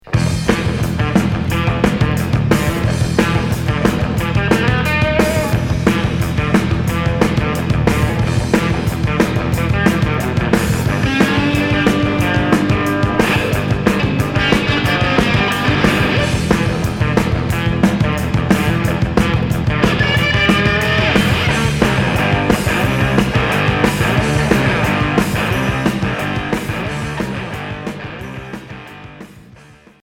Heavy rock punk Unique 45t retour à l'accueil